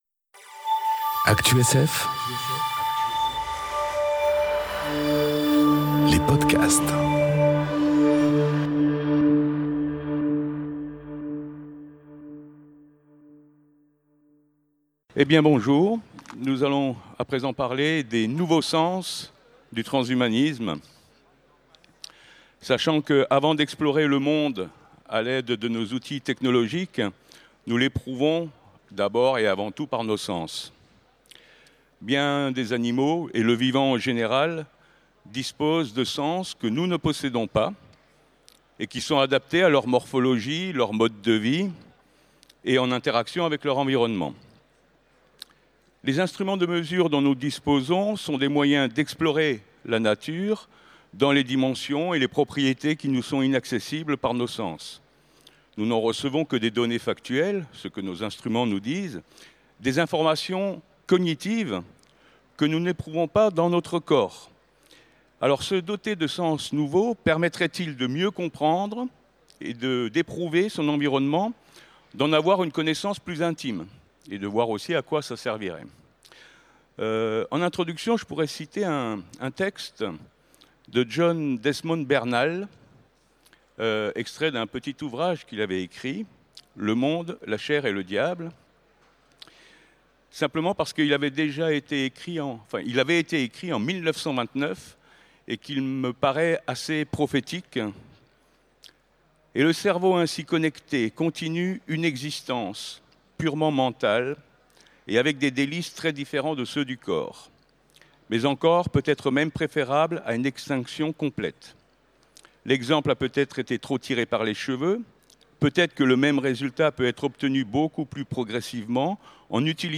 Conférence Transhumanisme 3 : Les nouveaux sens du transhumanisme enregistrée aux Utopiales 2018